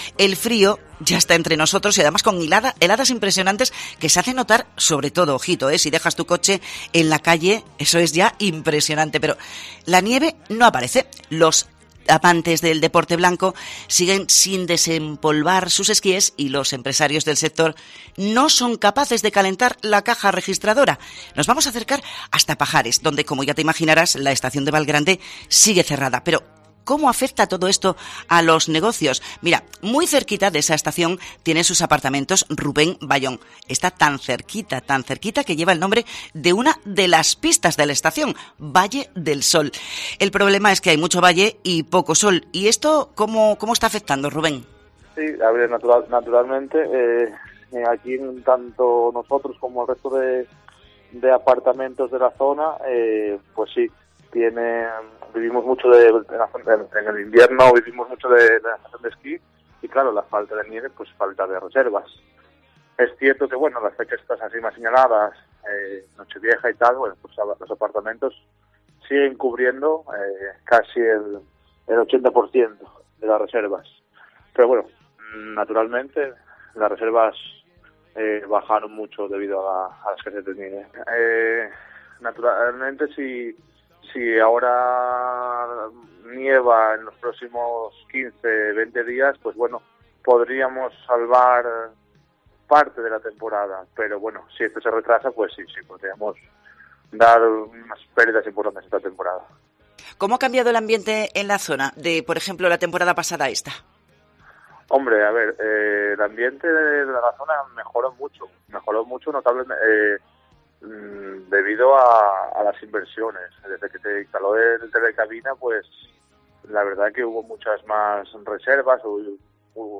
En COPE hemos hablado con propietarios de hoteles y apartamentos de la zona alta de Lena y Aller: "Nos están anulando reservas"
Hablamos con empresarios del sector de la nieve